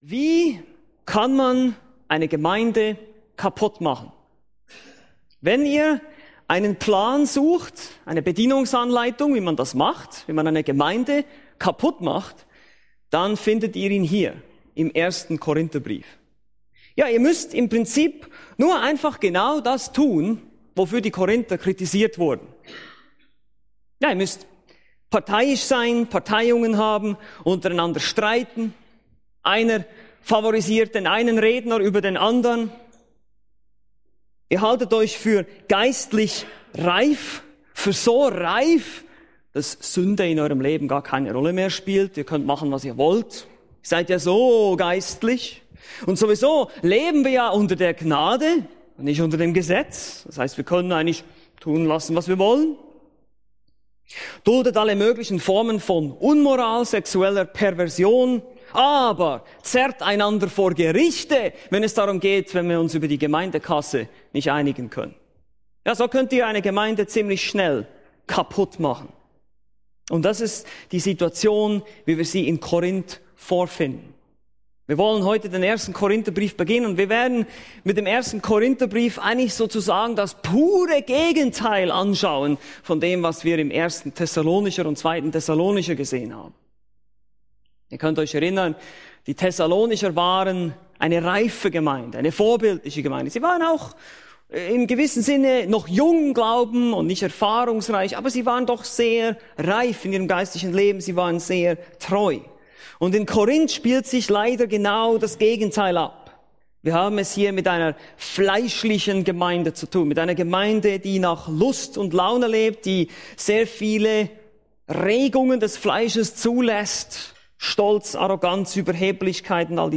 Bibelstunden - Bibelgemeinde Barnim